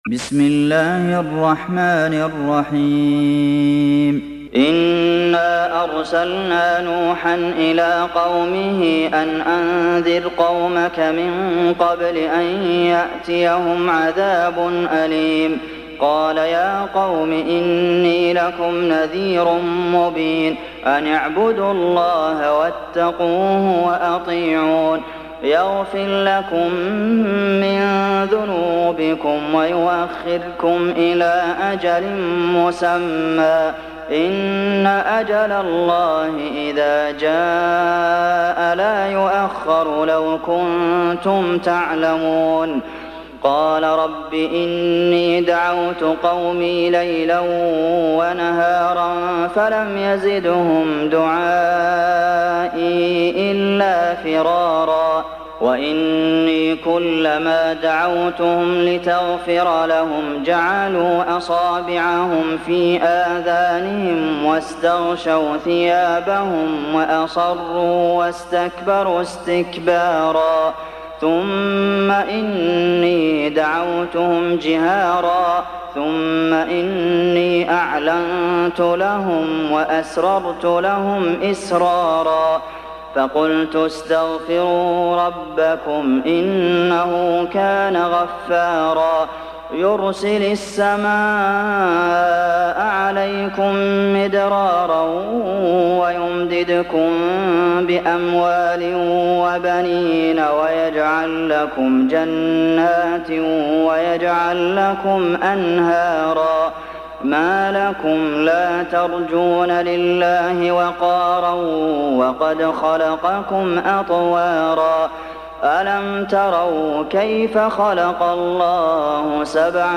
Surat Nuh Download mp3 Abdulmohsen Al Qasim Riwayat Hafs dari Asim, Download Quran dan mendengarkan mp3 tautan langsung penuh